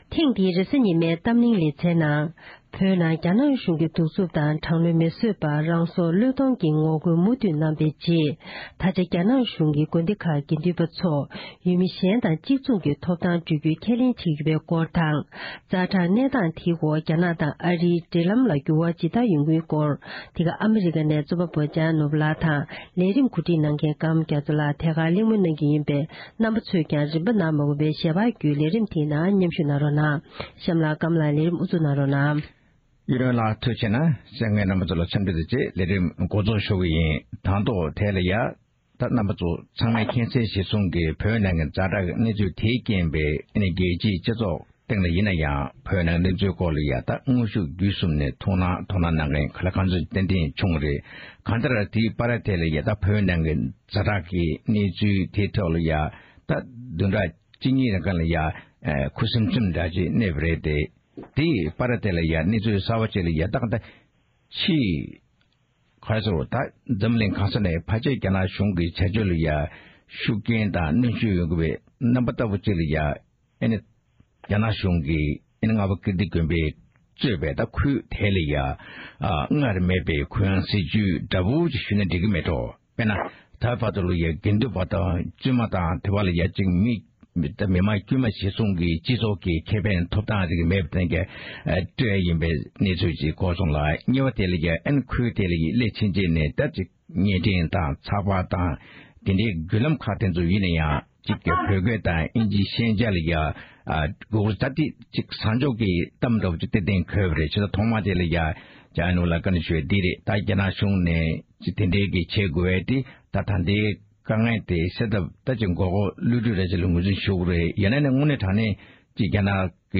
རེས་གཟའ་ཉི་མའི་གཏམ་གླེང་